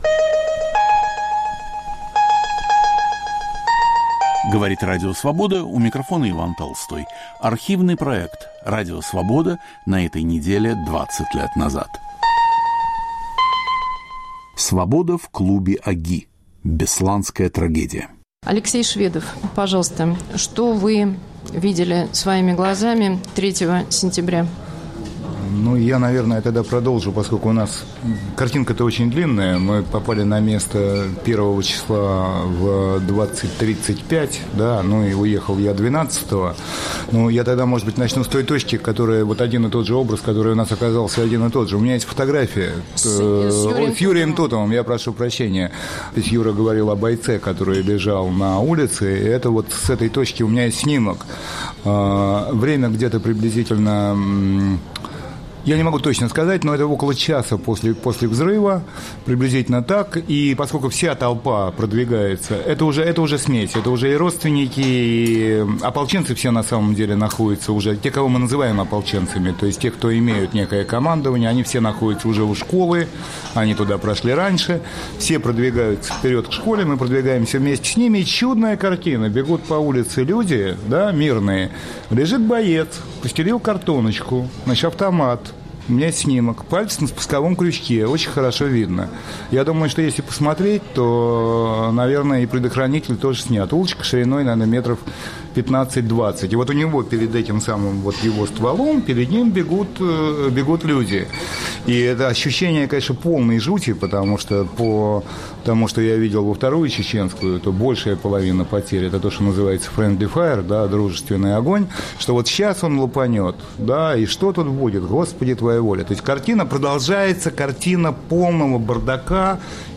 Месяц спустя после штурма бесланской школы №1 рассказывают свидетели события: фотографы и военные корреспонденты.